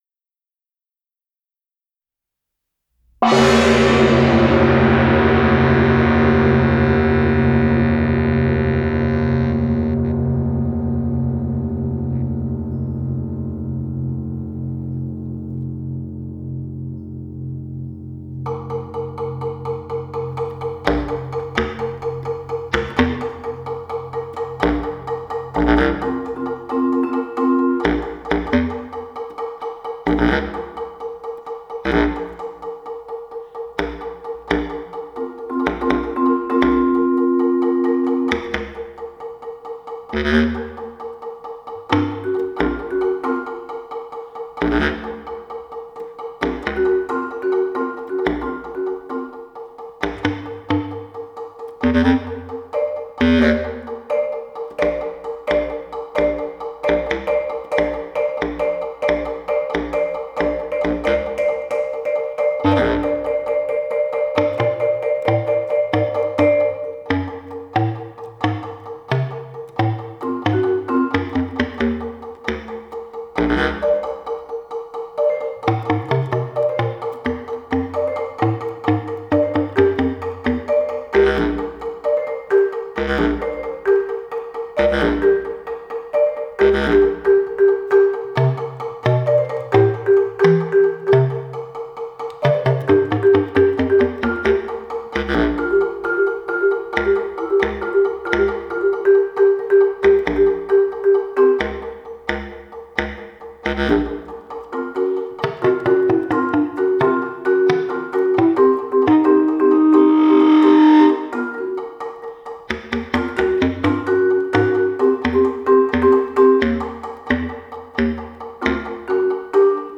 percussion